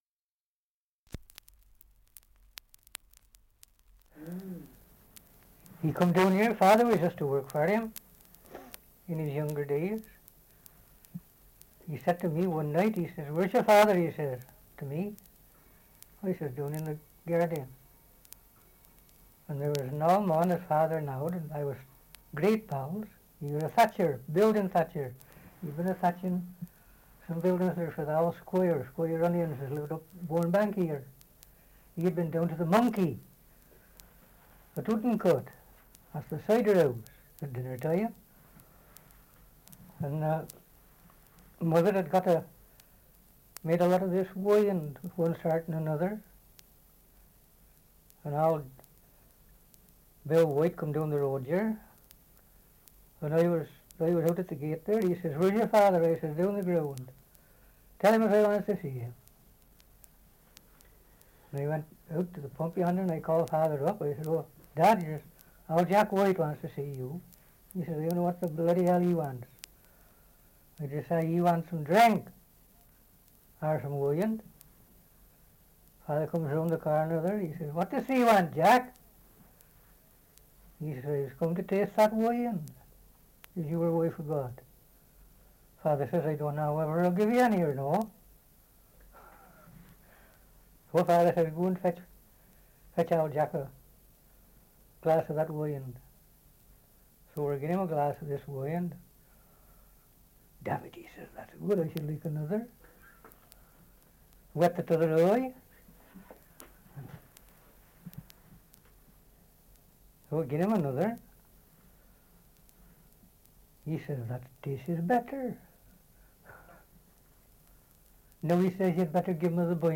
Survey of English Dialects recording in Earl's Croome, Worcestershire
78 r.p.m., cellulose nitrate on aluminium